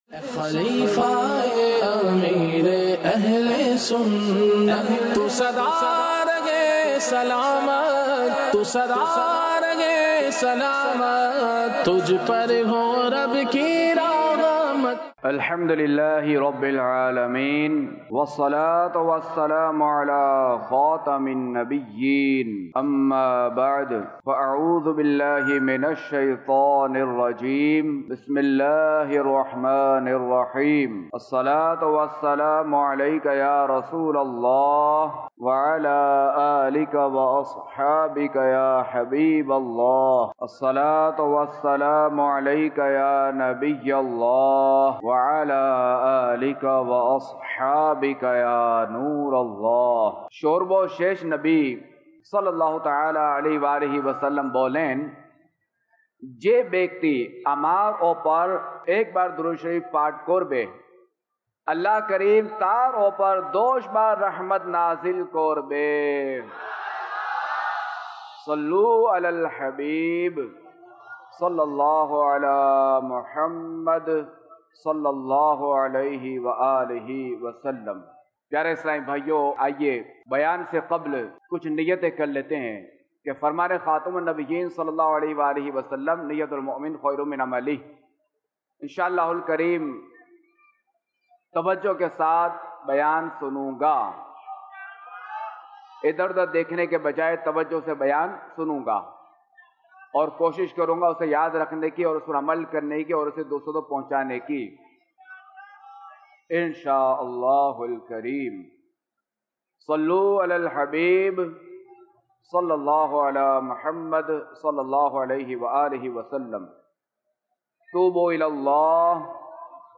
خلیفہ امیر اہلسنت کے بیانات - آخرت کی تیاری Time Duration